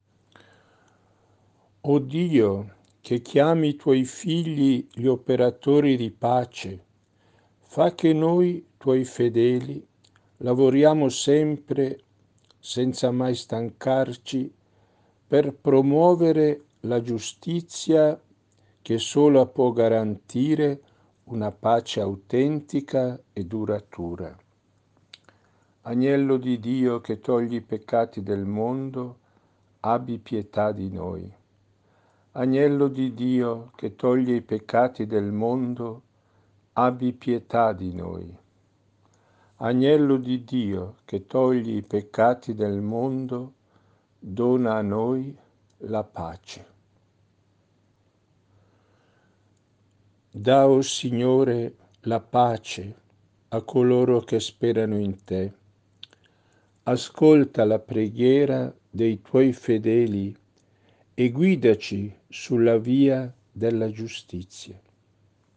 Ascolta la preghiera